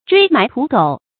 椎埋屠狗 注音： ㄔㄨㄟˊ ㄇㄞˊ ㄊㄨˊ ㄍㄡˇ 讀音讀法： 意思解釋： 殺人宰狗。指為非作歹和從事低賤的職業。